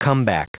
Prononciation du mot comeback en anglais (fichier audio)
Prononciation du mot : comeback